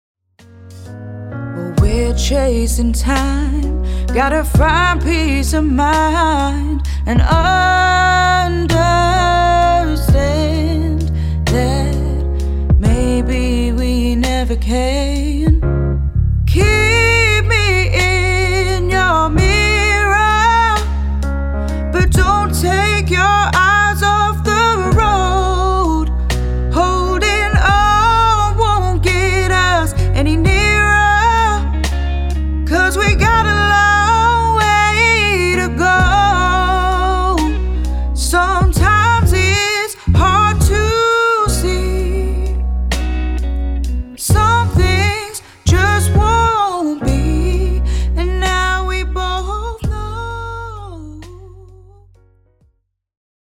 We love recording singers during a recording studio gift day voucher session.